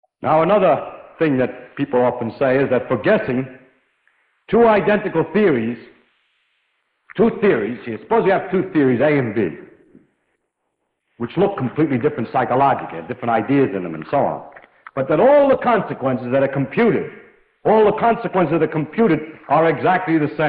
audio-denoising audio-to-audio
output-denoised.wav